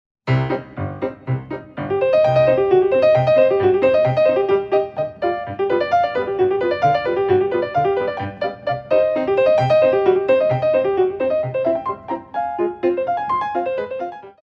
Ballet class music for Intermedite Level
Beautifully recorded on a Steinway and Sons Grand Piano